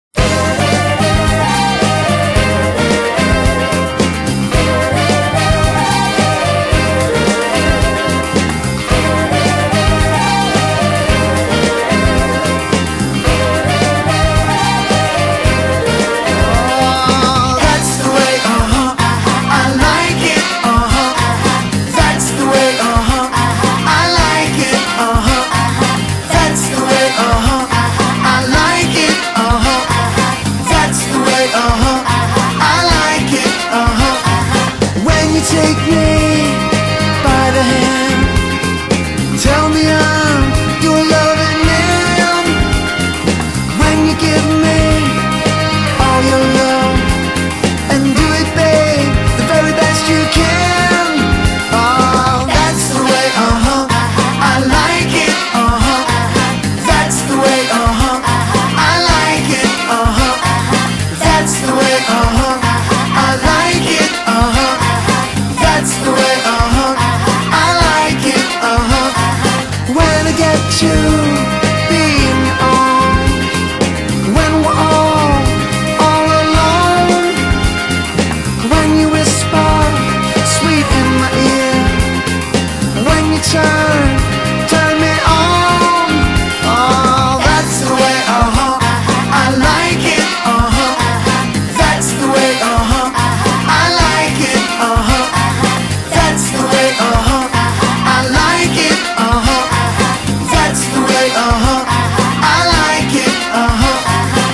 Lead Vocals
Musical Director/Guitar
Drums
Bass
Keyboards